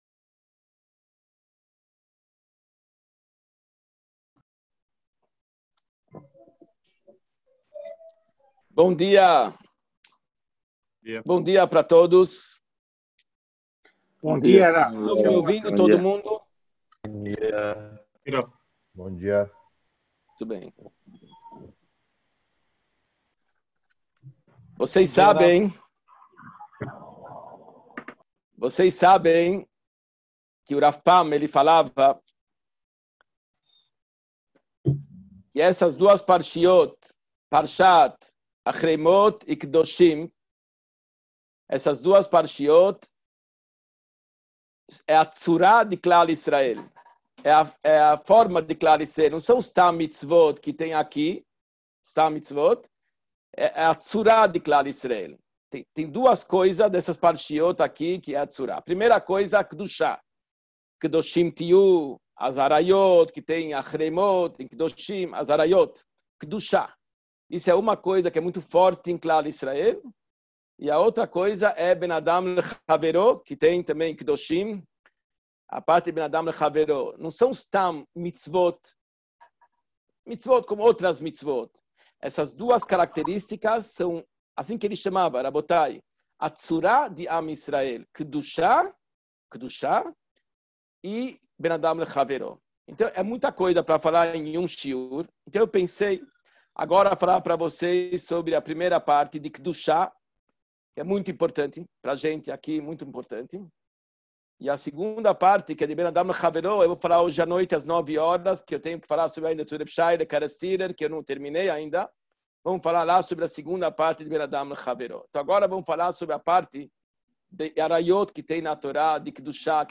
SHIUR PARSHA ACHAREI MOT KEDOSHIM – GUILUI ARAYOT (Época coronavírus)